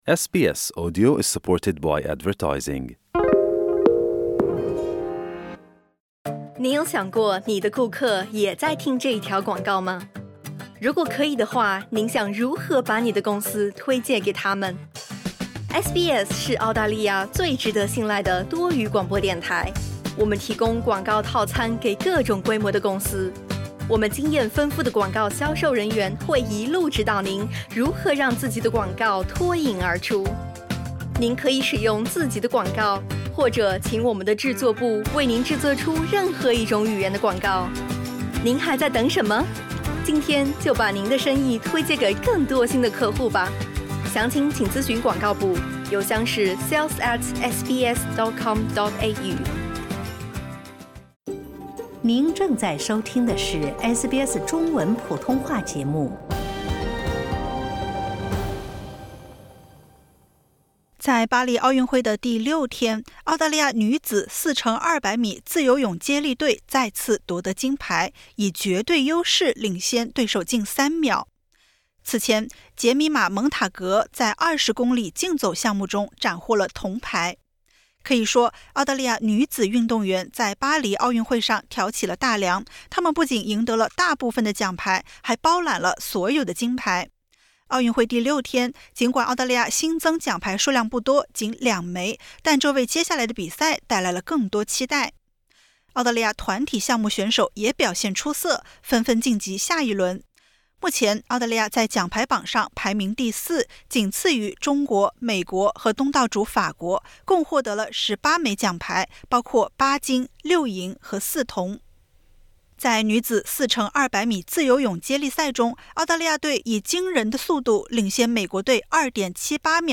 在巴黎奥运会第六日，澳大利亚女子4x200米自由泳接力队再次夺得金牌，以绝对优势领先对手近三秒。此前，杰米玛·蒙塔格在20公里竞走项目中斩获铜牌。点击 ▶ 收听完整报道。